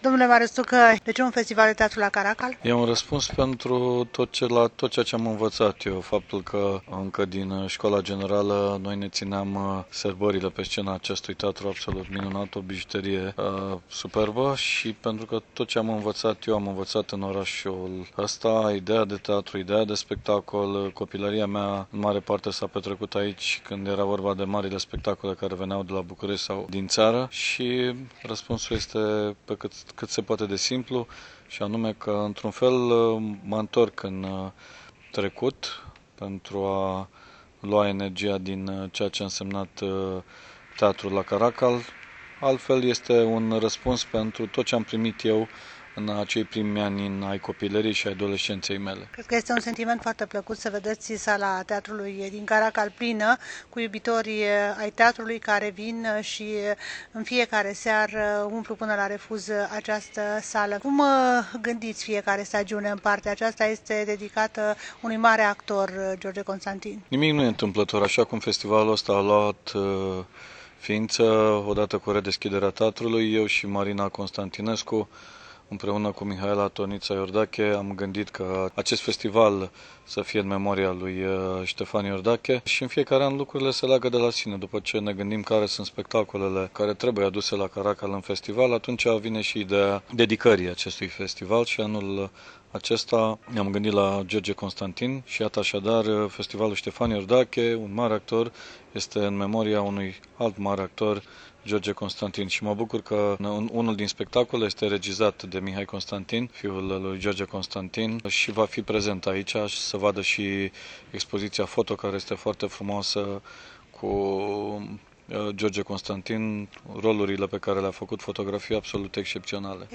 Marius Tucă a acordat un interviu în exclusivitate pentru Radio România Oltenia-Craiova